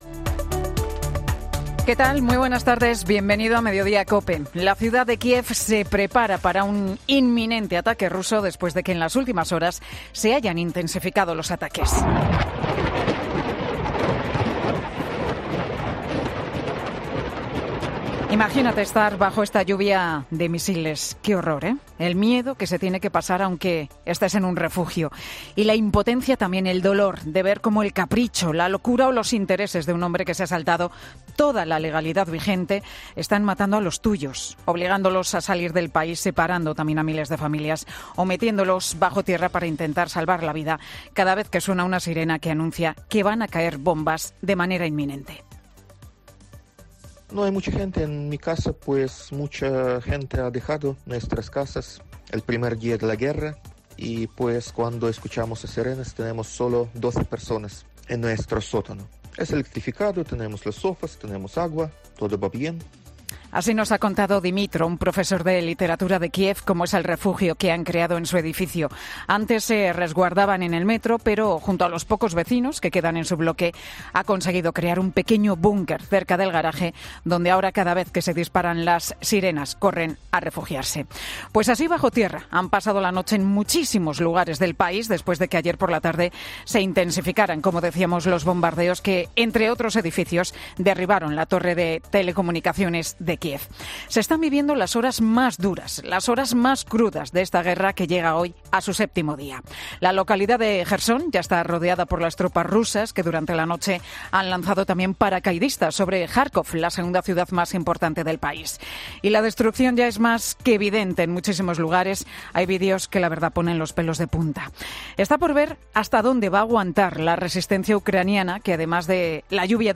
El monólogo de Pilar García Muñiz, en Mediodía COPE